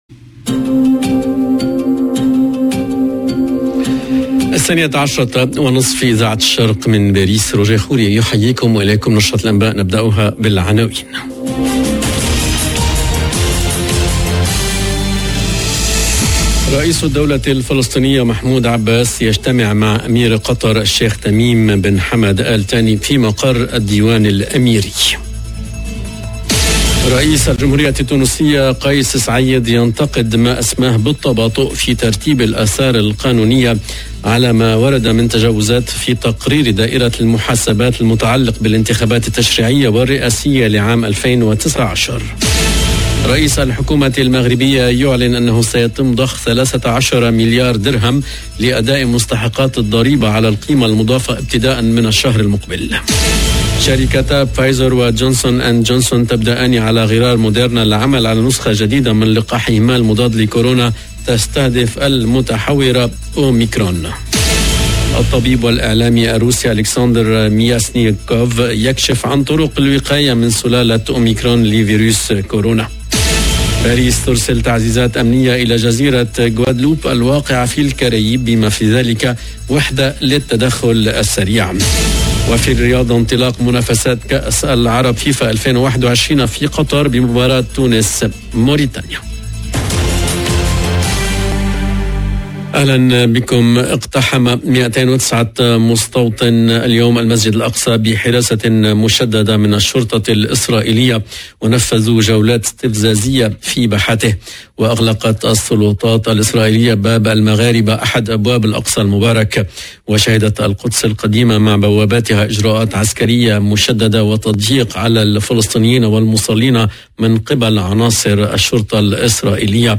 LE JOURNAL EN LANGUE ARABE DE MIDI 30 DU 30/11/21